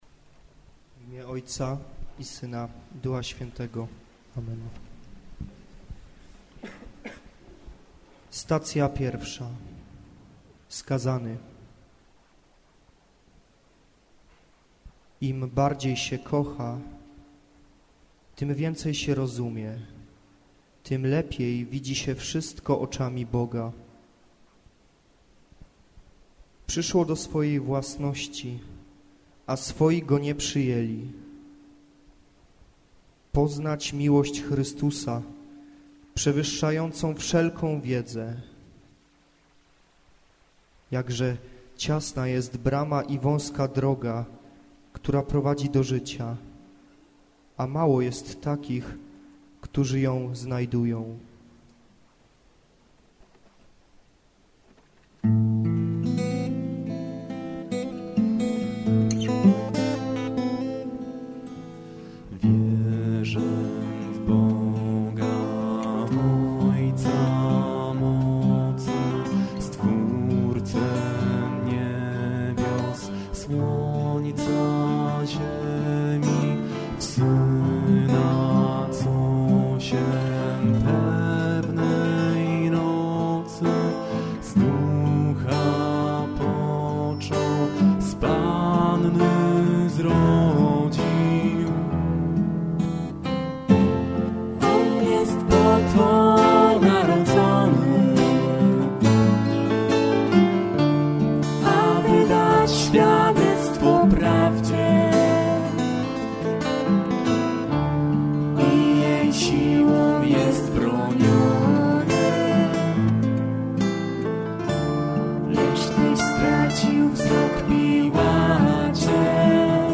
WokalGitaraKeyboard